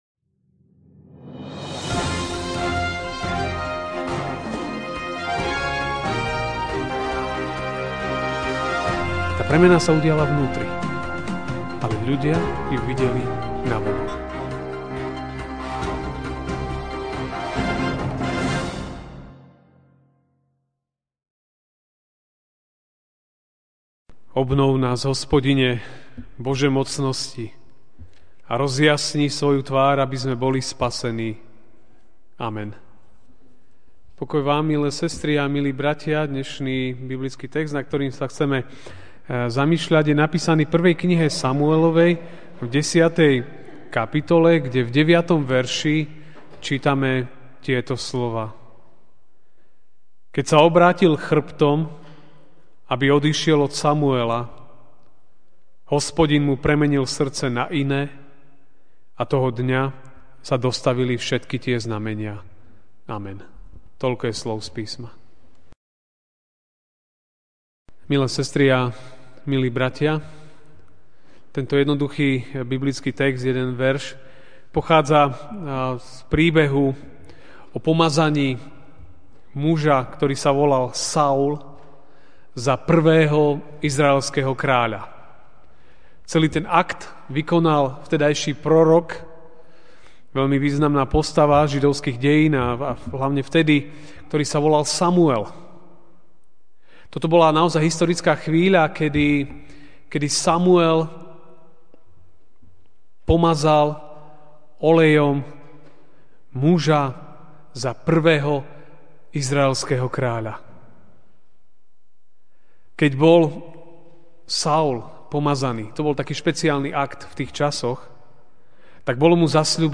apr 30, 2017 Hospodin premieňa srdce MP3 SUBSCRIBE on iTunes(Podcast) Notes Sermons in this Series Večerná kázeň: Hospodin premieňa srdce (1. Sam. 10, 9) Keď sa obrátil chrbtom, aby odišiel od Samuela, Hospodin mu premenil srdce na iné, a toho dňa sa dostavili všetky tie znamenia.